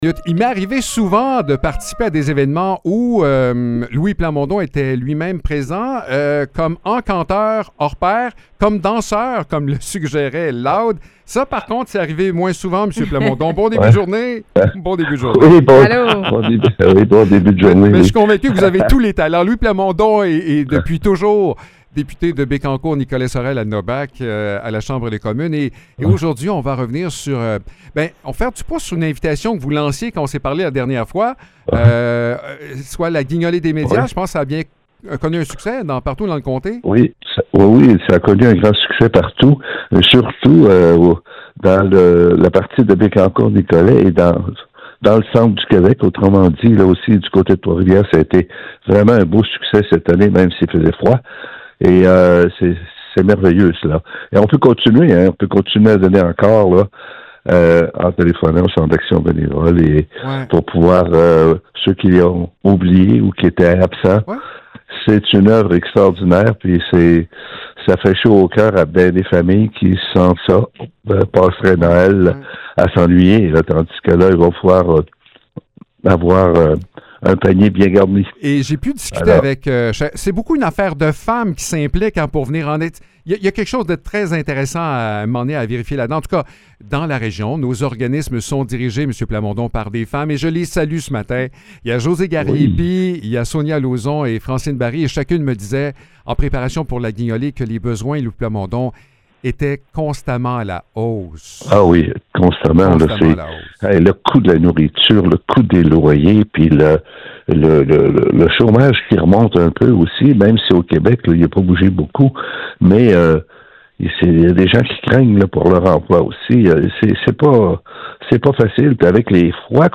Louis Plamondon, député de Bécancour–Nicolet–Saurel–Alnôbak, nous rappelle que cette période de l’année est idéale pour faire preuve de générosité et poser des gestes qui font une réelle différence pour les personnes dans le besoin.